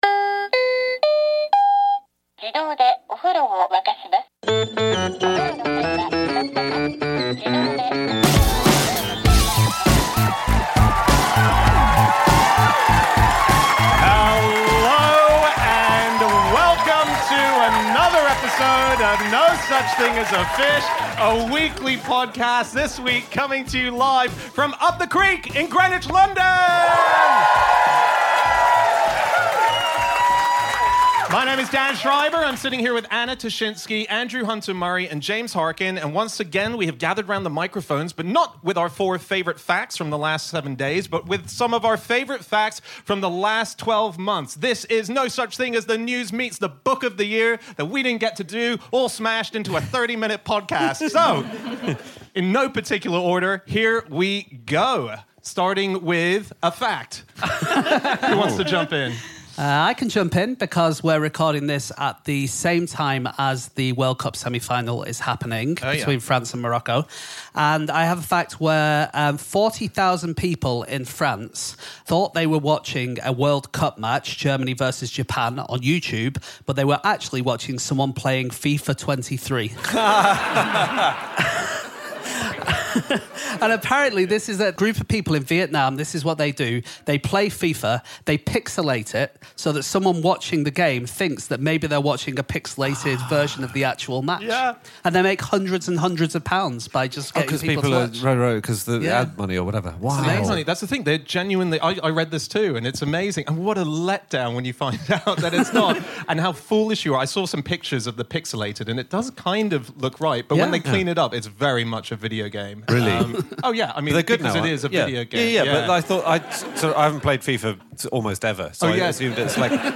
Live from the Up the Creek in Greenwich